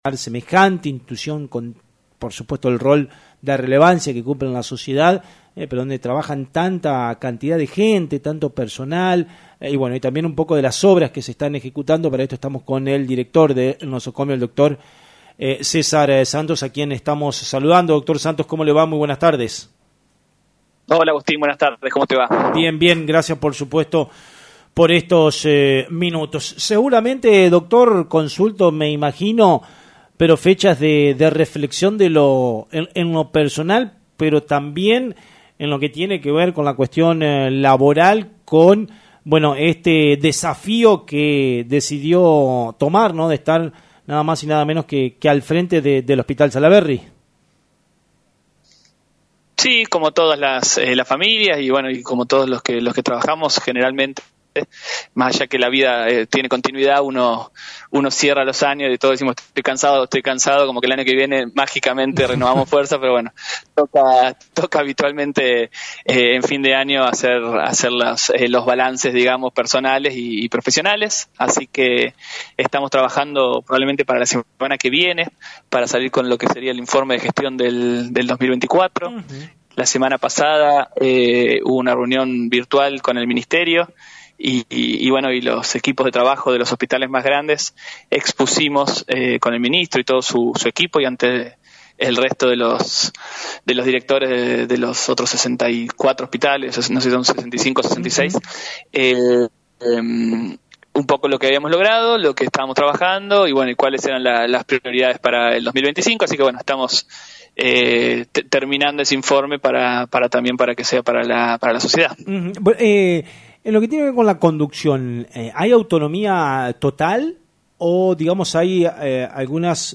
charló con el programa “Radionoticias”, anticipando algunos aspectos de la evaluación.